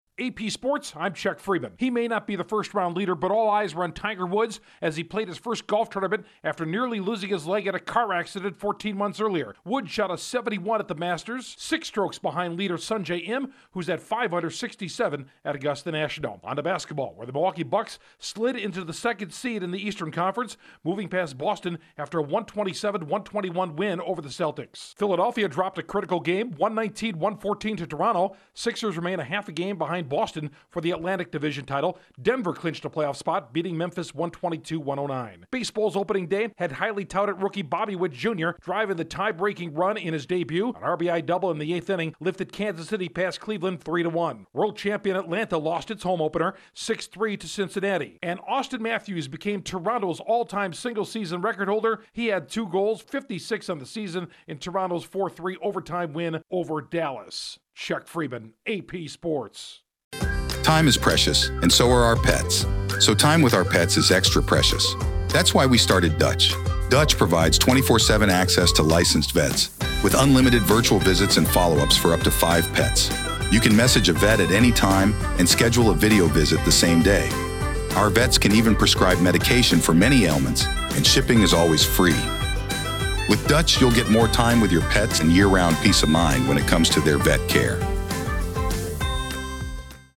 Update on the latest sports